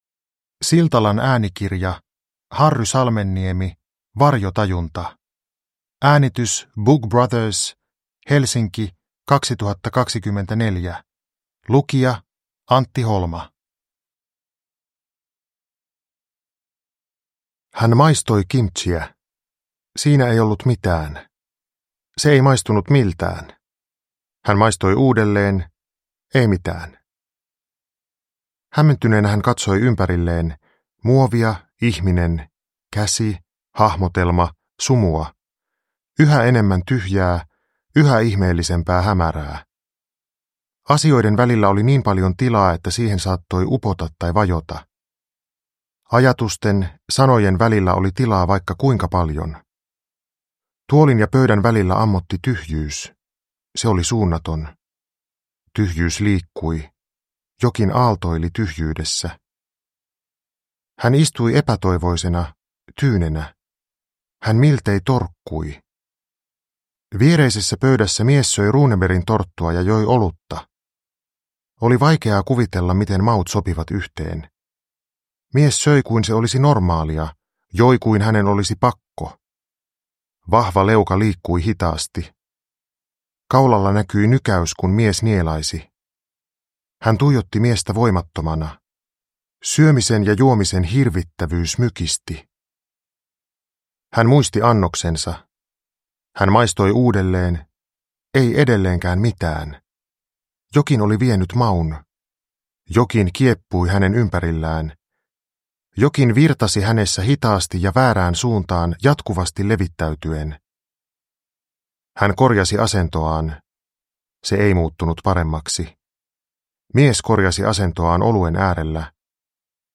Varjotajunta – Ljudbok
Uppläsare: Antti Holma